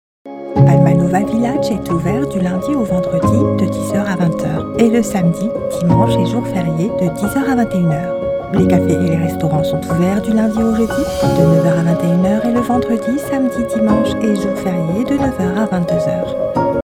On Hold, Professional Voicemail, Phone Greetings & Interactive Voice Overs
Yng Adult (18-29) | Adult (30-50)